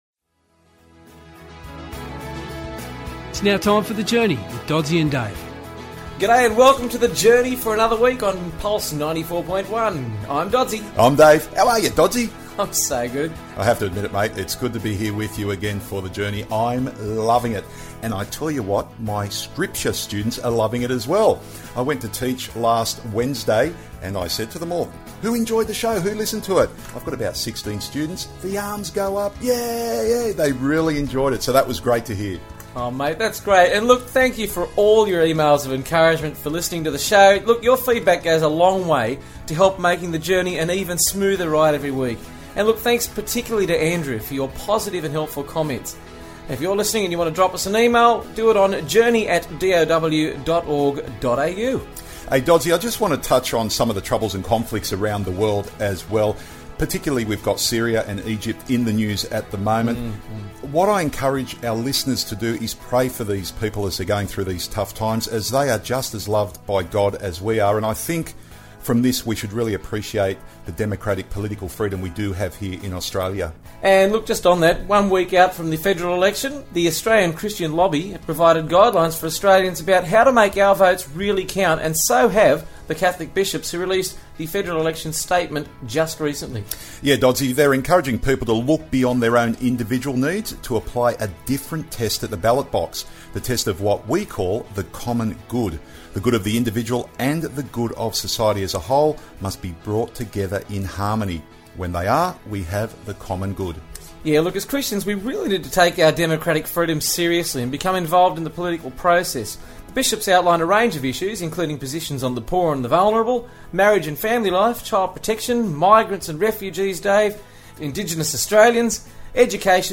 There are also regular interviews highlighting the good things being done in the Church and its agencies.